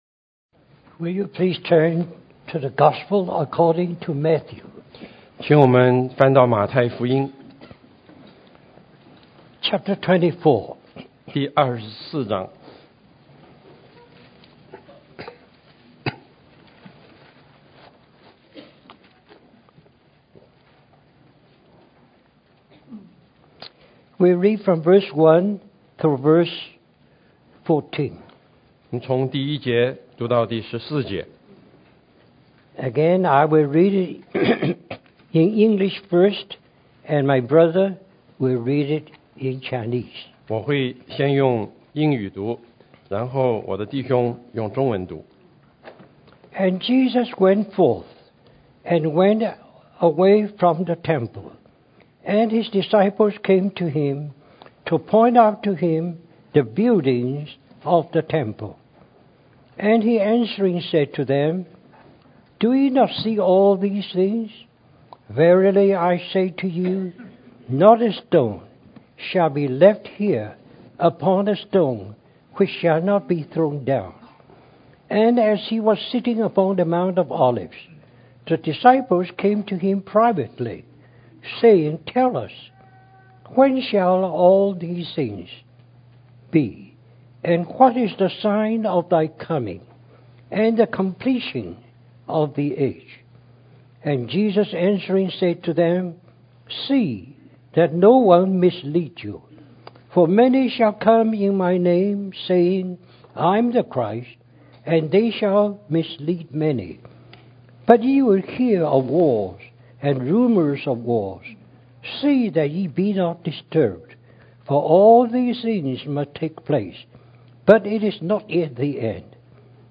Our brother shares a series of four messages on The Gospel of the Kingdom